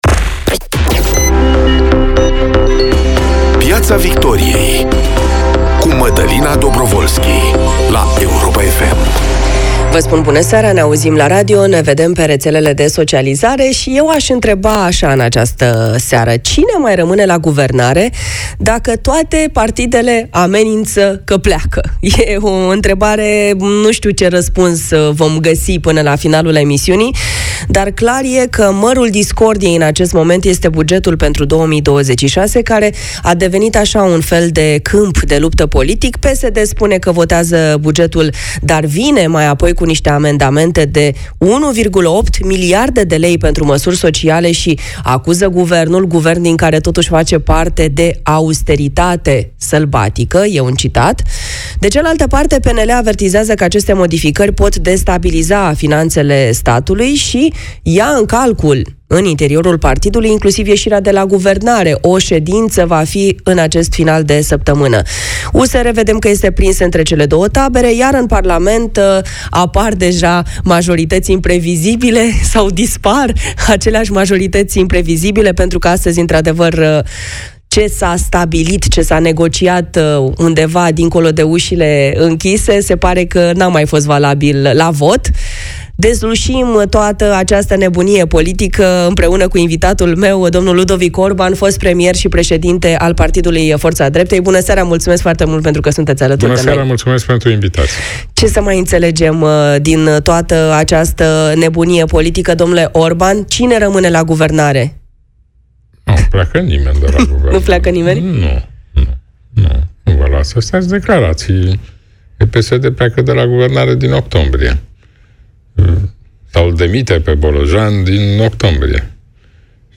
Cătălin Drulă, liderul USR, este invitat în studioul Europa FM.